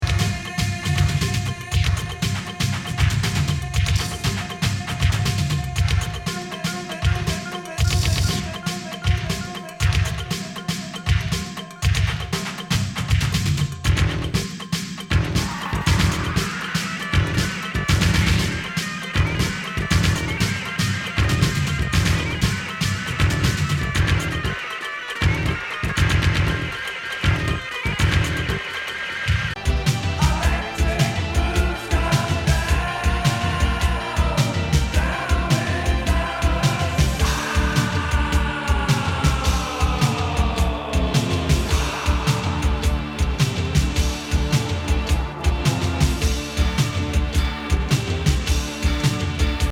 エレクトロ工業ノイジー・ボディ・ビート！